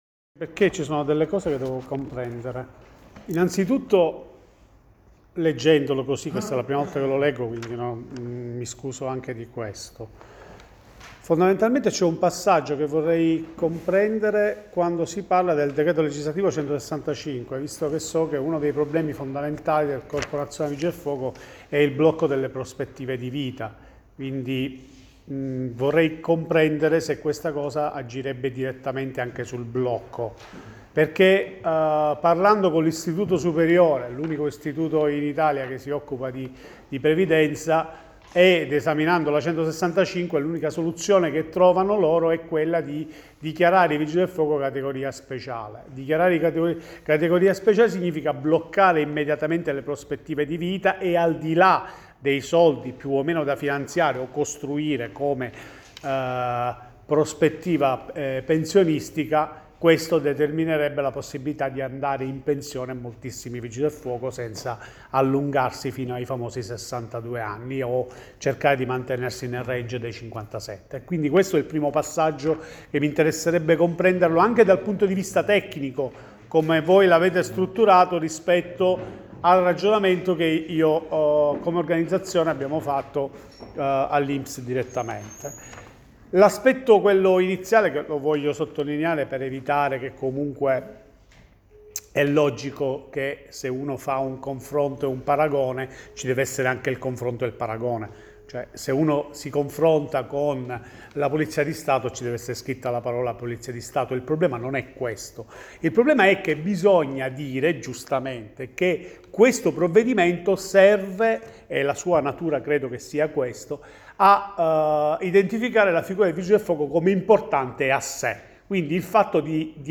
Ascolta negli allegati l'audio dell'intervento di USB all'incontro del 30.03.2019 Allegati USB-VV.F._la_legge_chi_la_legge.pdf Audio_intervento_USB_30.03.2019.mp3